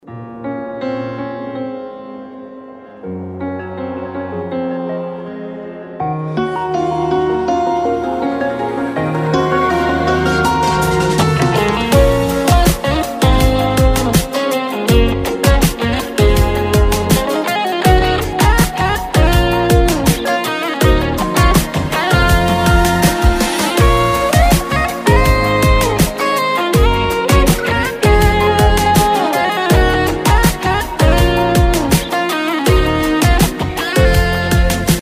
رینگتون احساسی و بی کلام